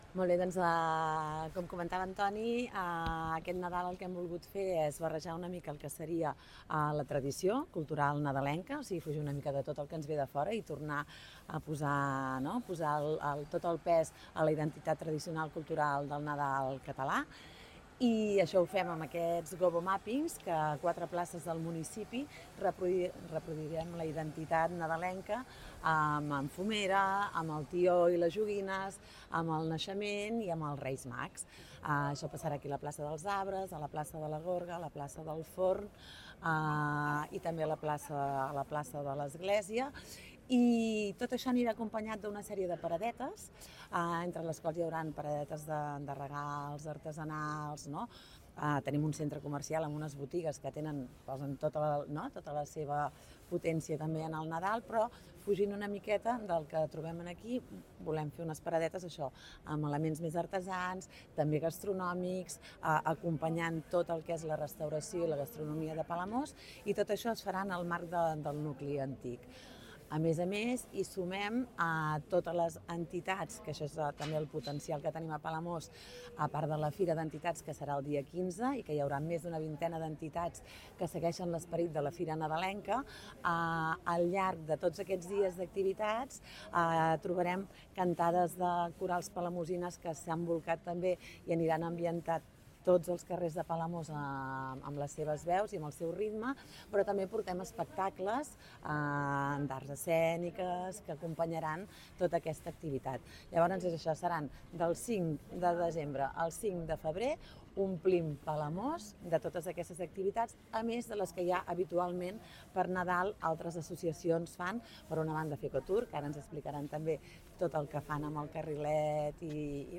La regidora de cultura de l’Ajuntament de Palamós, Núria Botellé, ha admès que es vol celebrar el Nadal a Palamós, però recuperant l’essència de la tradició al municipi.